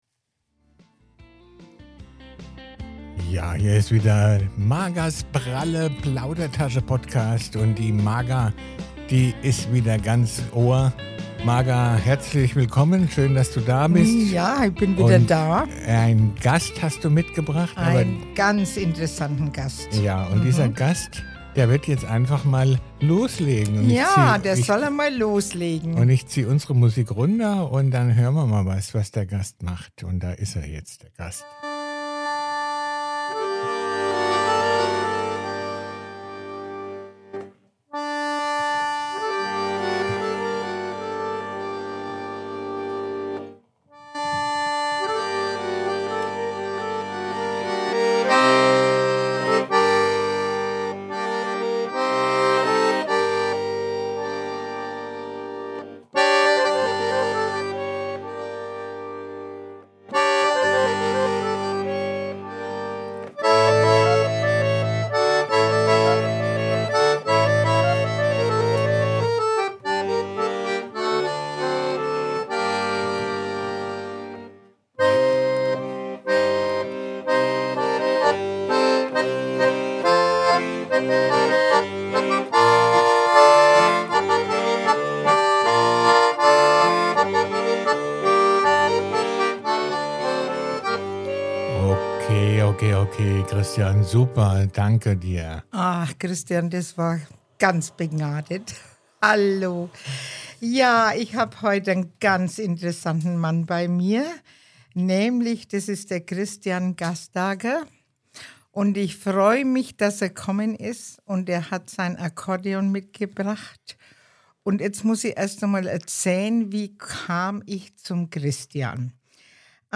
Freuen Sie sich auf inspirierende Einblicke, herzhafte Anekdoten und einzigartige Klänge, die Sie so schnell nicht vergessen werden.